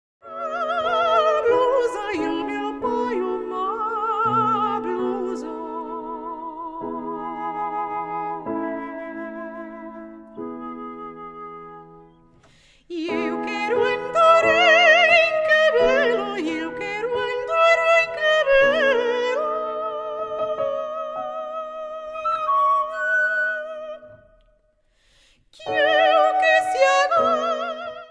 Portuguese Soprano
PORTUGUESE SONGS